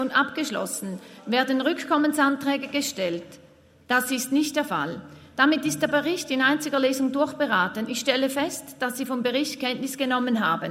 19.9.2023Wortmeldung
Schöb-Thal, Ratspräsidentin, stellt Kenntnisnahme vom Prüfbericht zum Prüfprogramm 2021 des Regulierungscontrollings fest.